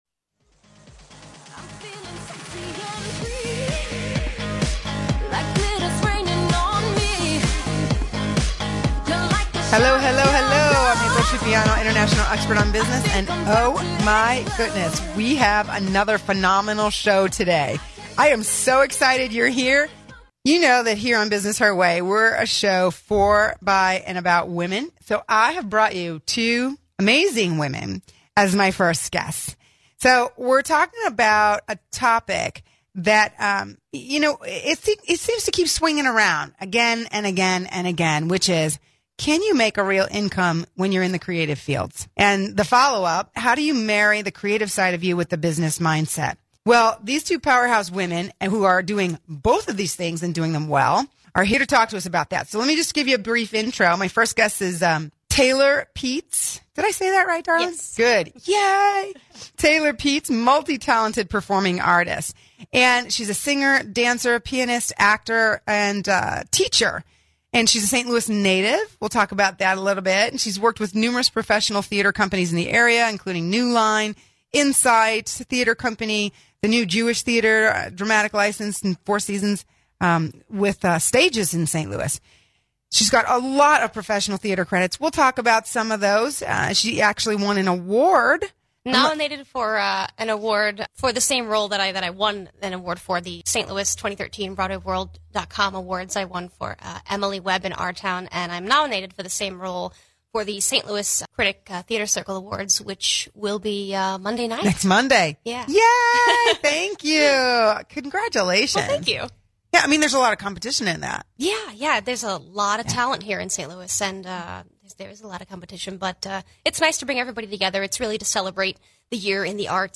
Here is part one of that interview (9min 30sec):